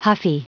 Prononciation du mot huffy en anglais (fichier audio)
Prononciation du mot : huffy